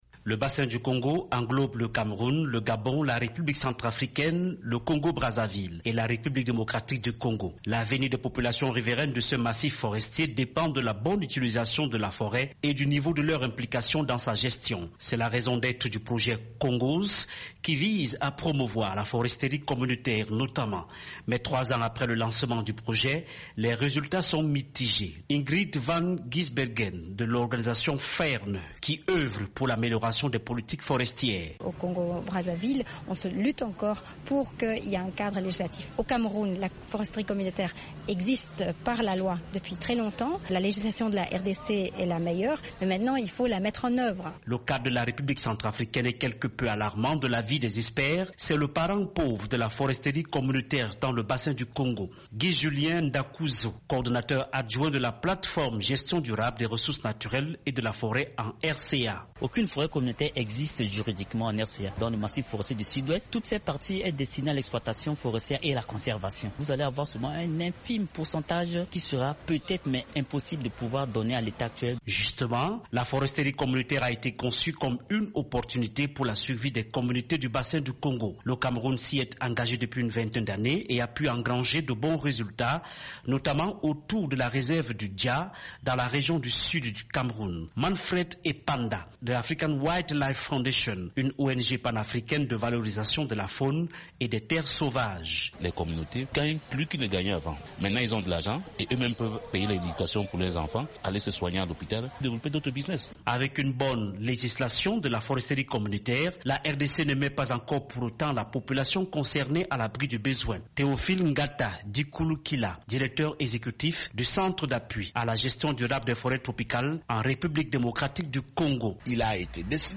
Pour y parvenir, un projet dénommé "CONGOS", a été lancé il y a trois ans. Son bilan vient d’être dressé au Cameroun.